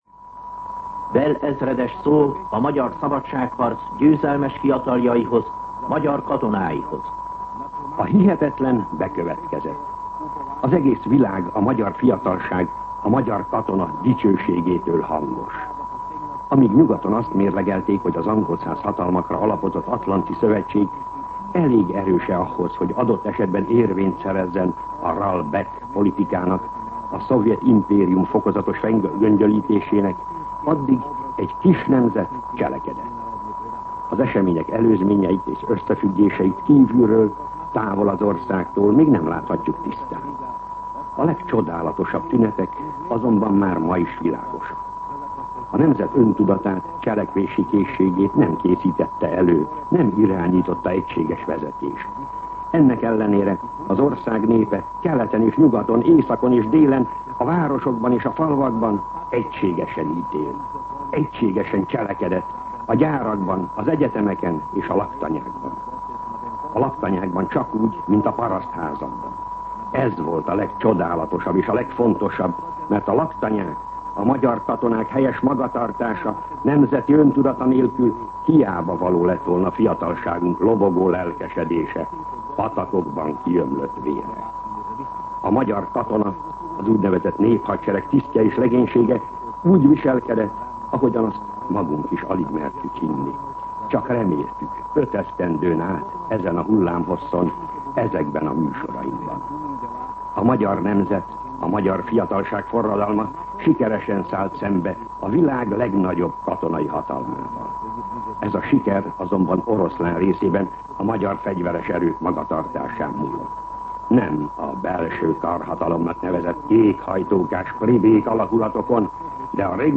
MűsorkategóriaKommentár